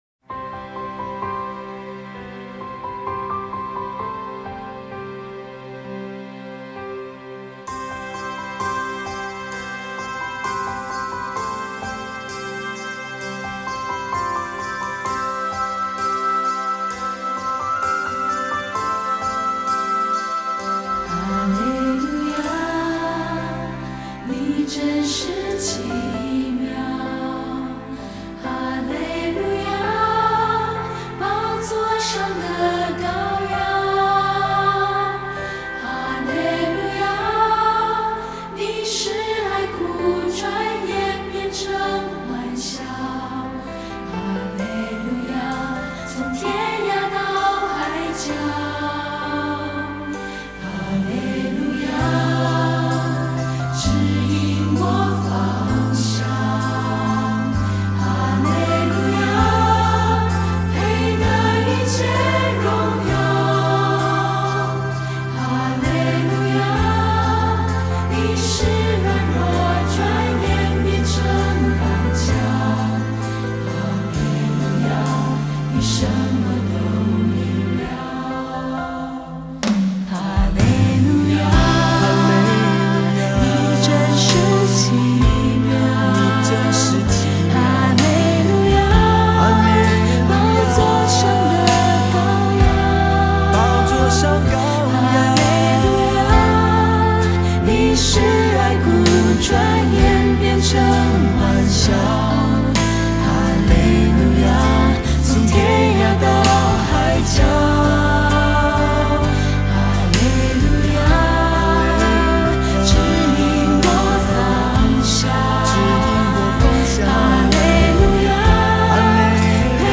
前奏 → 主歌 1 → 主歌 2 → 主歌 1 → 主歌 2 → 主歌 1 → 主歌 2 → 尾段